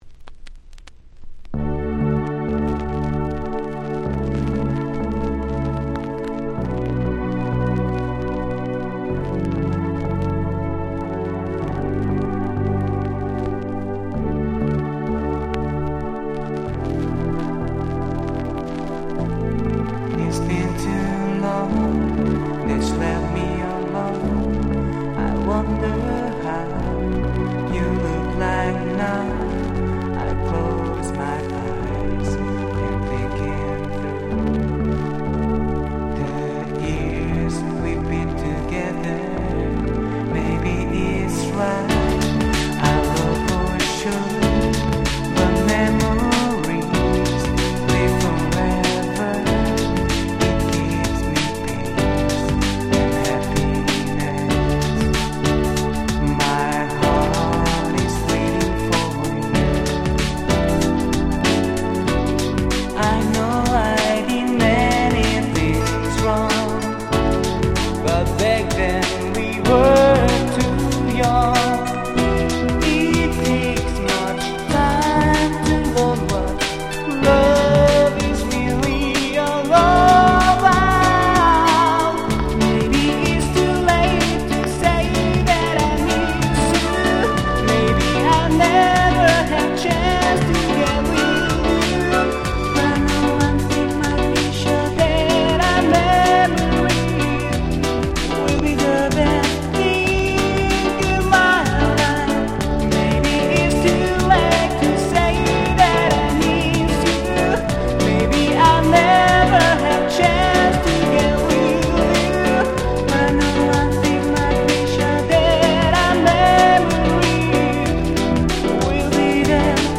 【Condition】C (全体的に薄いスリキズが多めですがDJ Play可。
※両面共に全トラック最初から最後まで試聴ファイルを録音してございます。
93' Very Nice Ground Beat !!